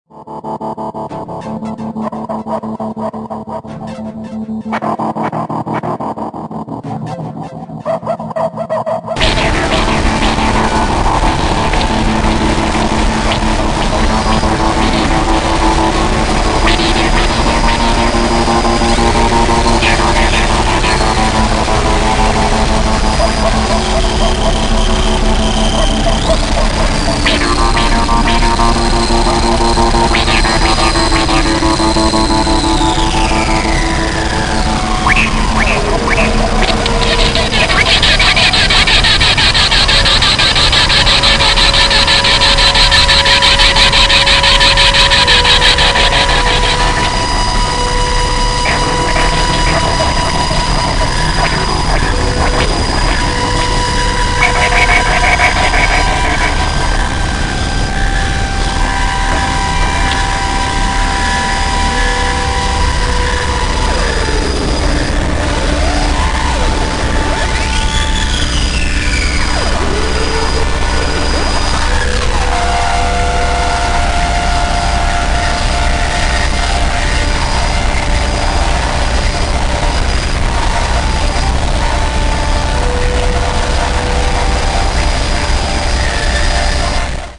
wall noise
dark ambient/sinister guitar drone